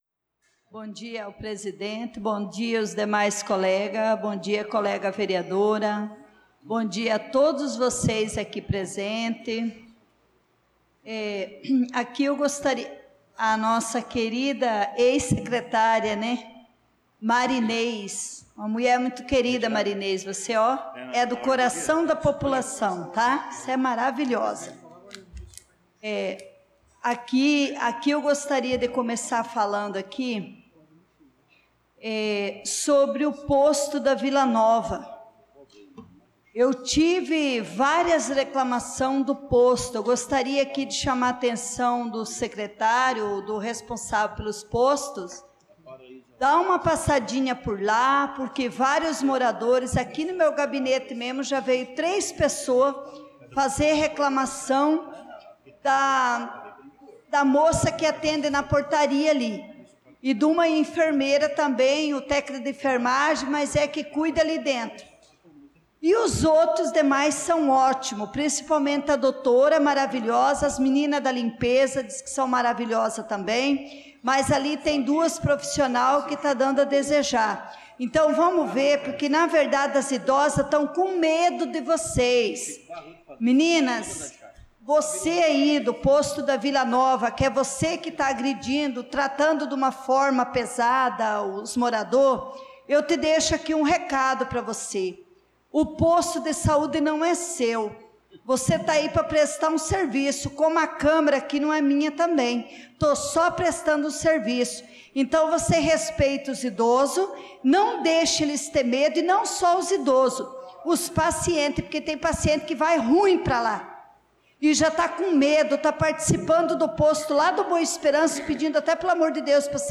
Pronunciamento da vereadora Leonice Klaus na Sessão Ordinária do dia 02/06/2025